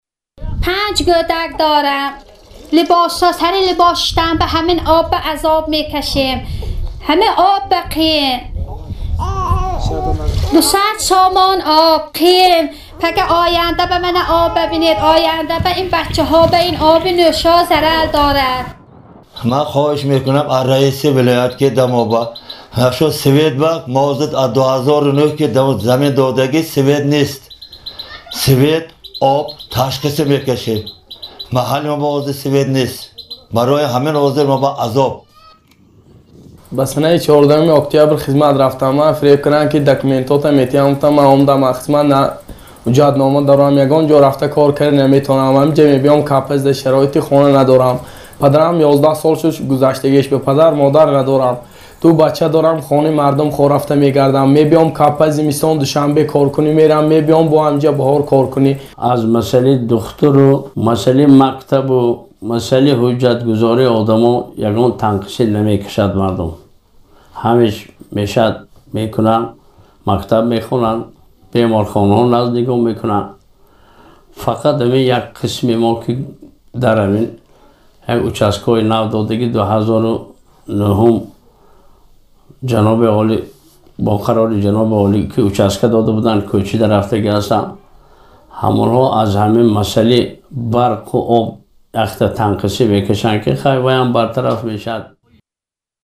Дар идома садои теъдоди аз сокинони Навобод ва раиси ноҳияи Навободро бишнавед: